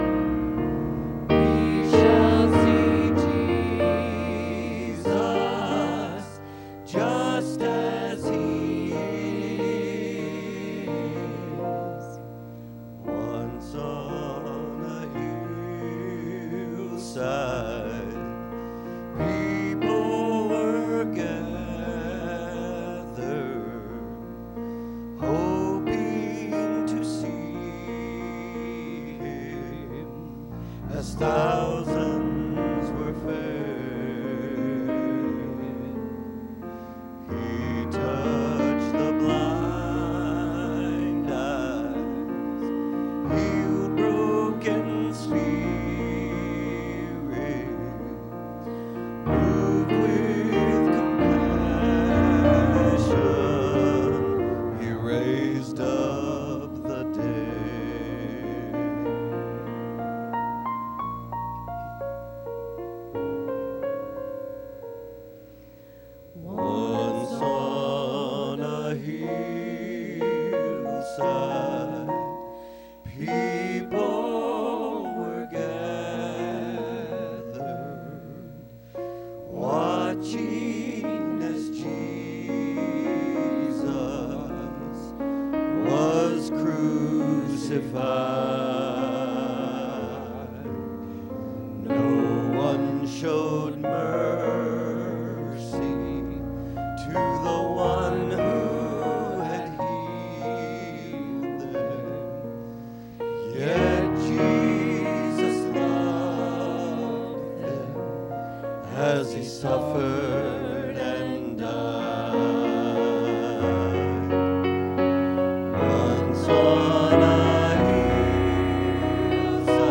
Listen to a quartet sing as you watch these two beautifully created families of birds: